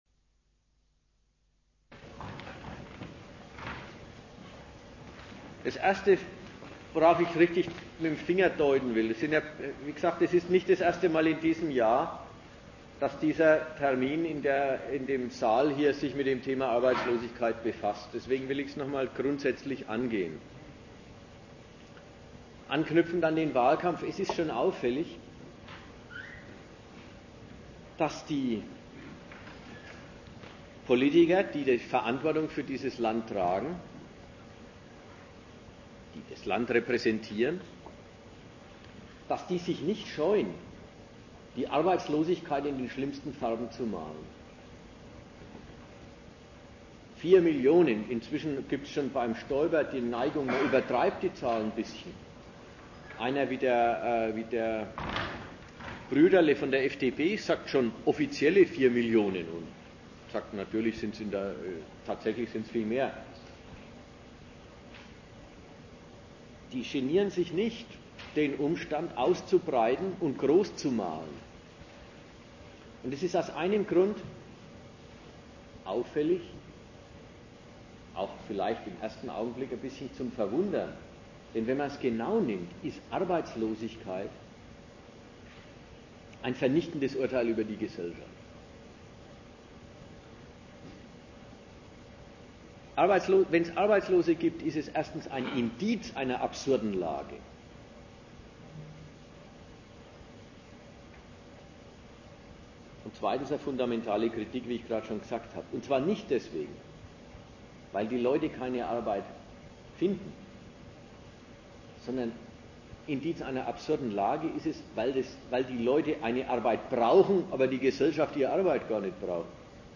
Vortrag zum Artikel: Der 'große Wurf' der Hartz-Kommission: Das neue Arbeitsamt - vermarkten statt vermitteln in Gegenstandpunkt 3/02